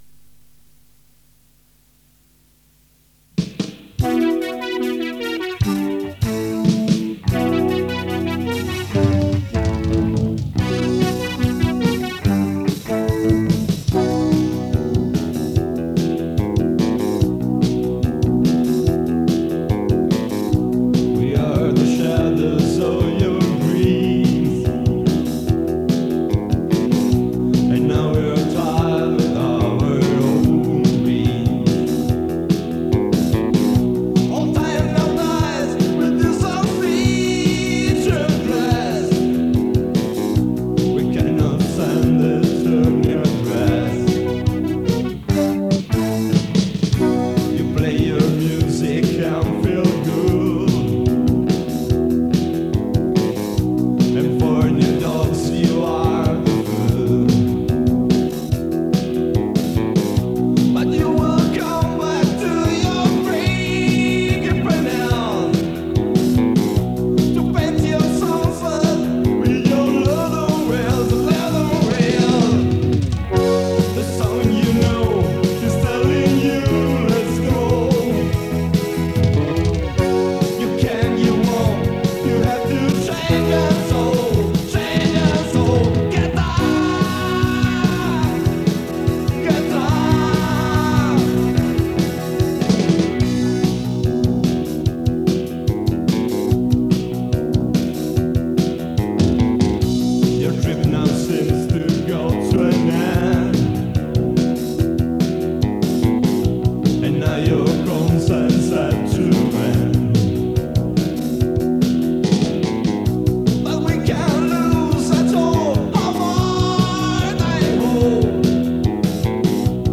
da una registrazione in studio.
Rock band palermitana della metà anni '80.